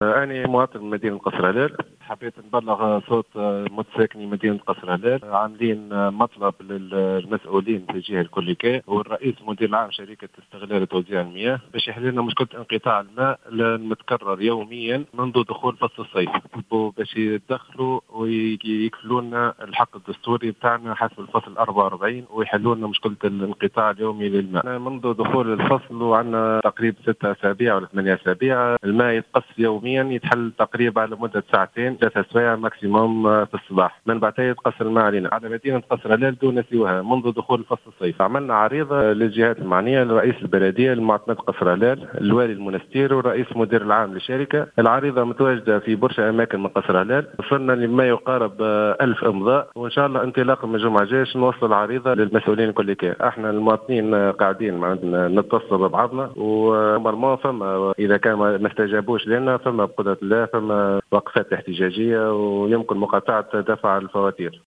أحد متساكني قصر هلال